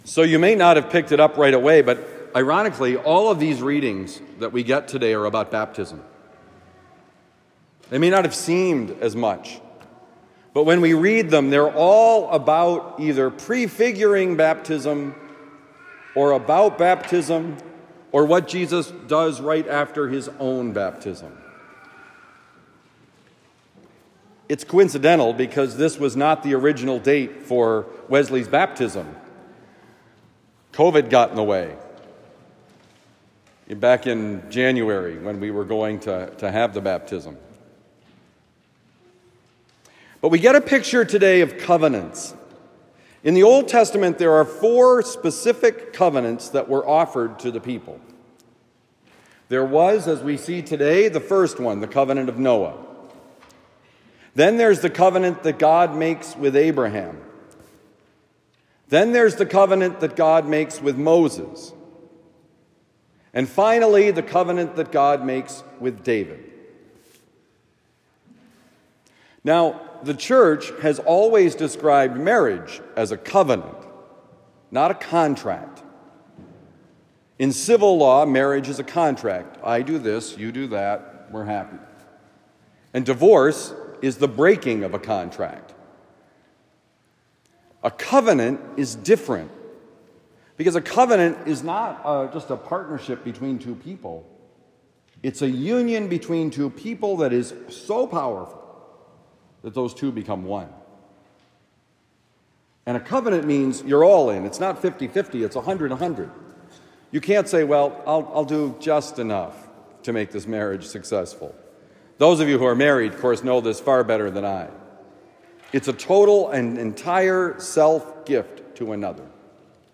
The Covenant: Homily for Sunday, February 18, 2024